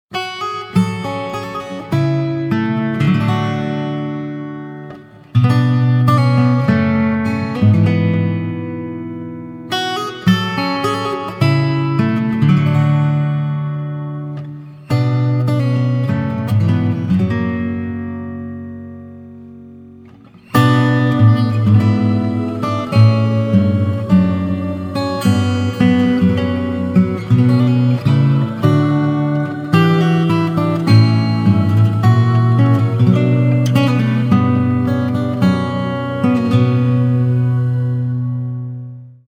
• Качество: 192, Stereo
гитара
спокойные
без слов
красивая мелодия
Folk Rock
Neo-Folk